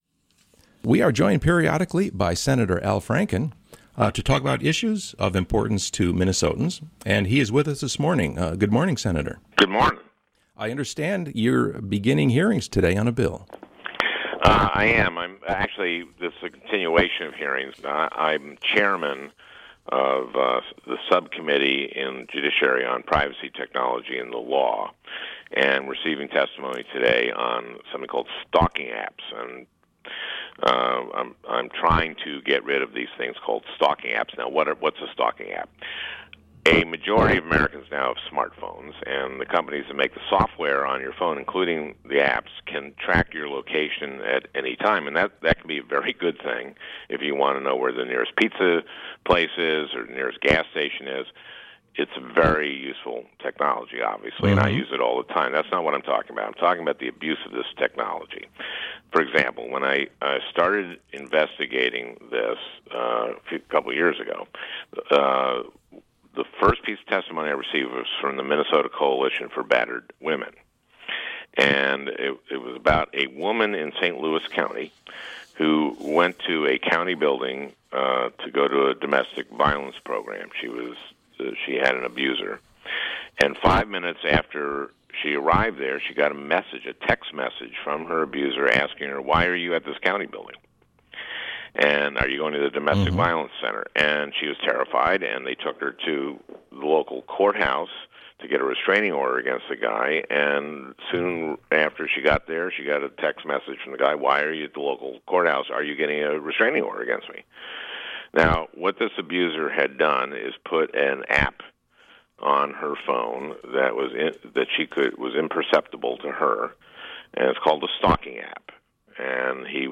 Senator Franken Discusses Concerns Over "Stalking Apps"